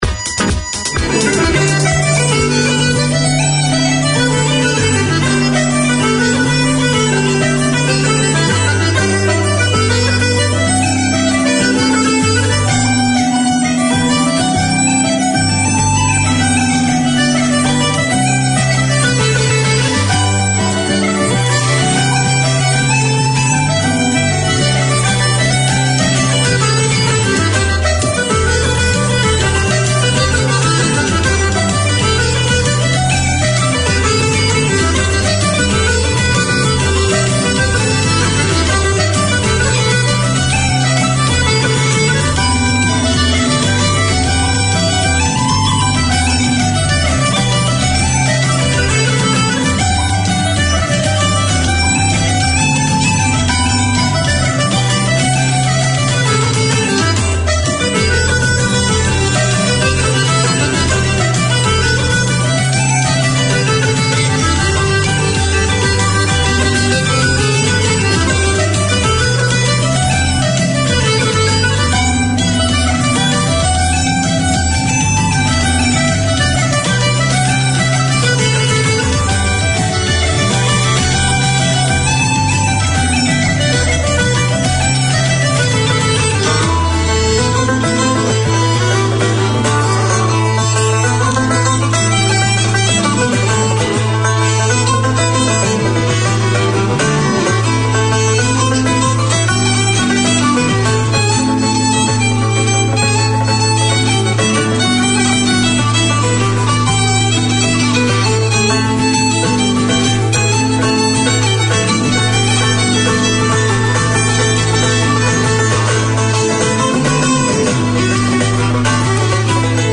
Radio made by over 100 Aucklanders addressing the diverse cultures and interests in 35 languages.
Featuring a wide range of Irish music and the occasional guest, including visiting Irish performers, politicians, sports and business people.